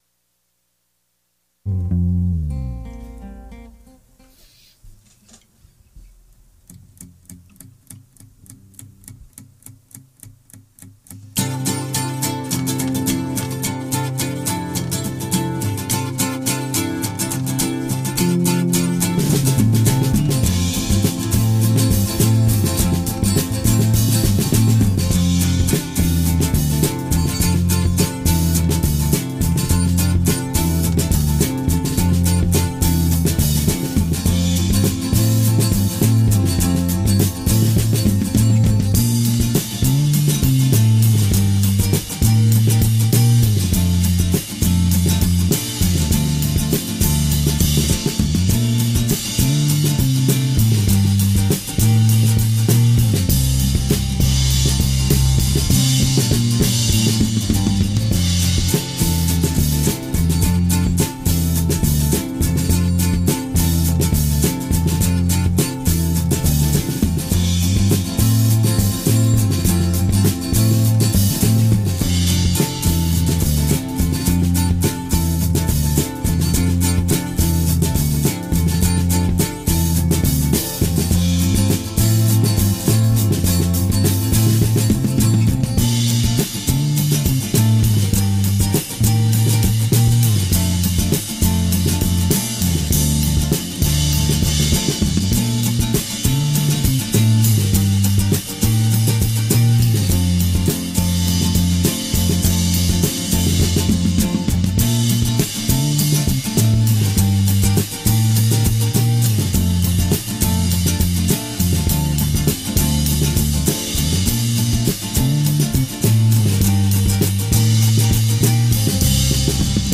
just used a tascam 244 with an alesis microverb 4 tape delay on guitars, with the same mxl 9000 mic on them and drums. single mic drum track, DI bass, and double tracked guitars without any metronome used. i actually tracked guitars first. no bounces.